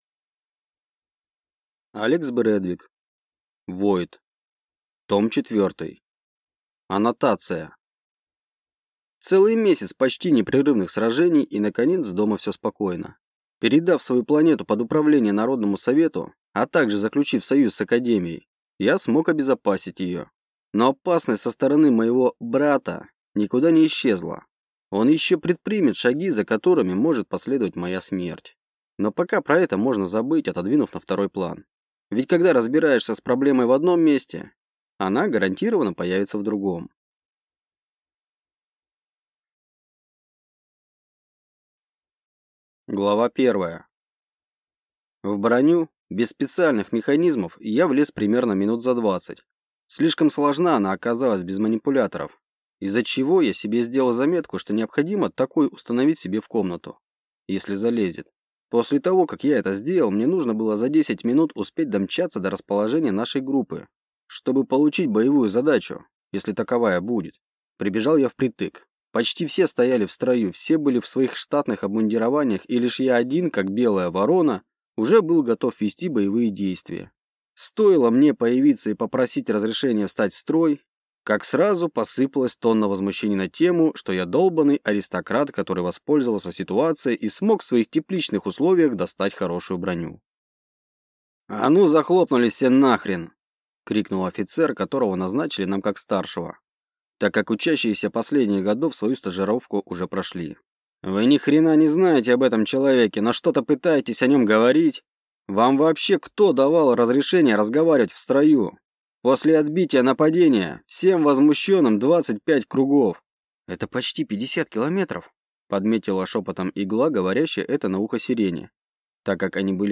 Аудиокнига Войд. Том 4 | Библиотека аудиокниг